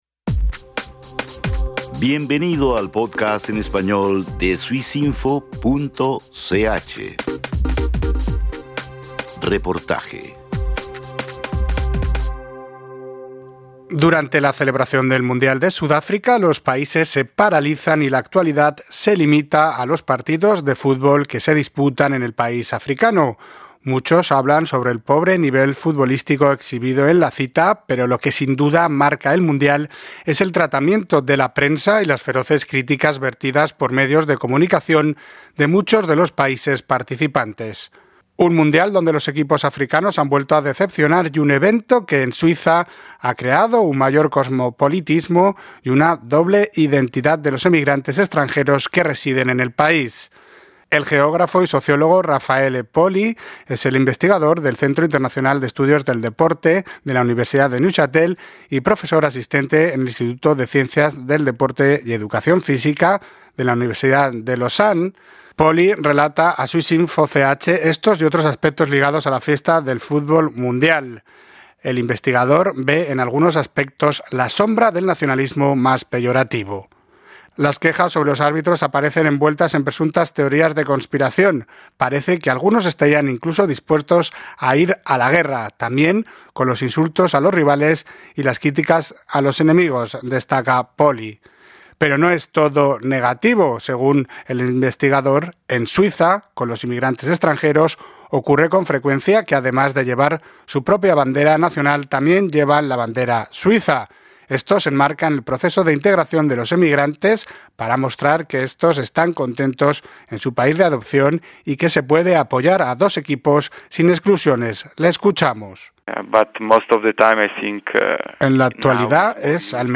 Un reportaje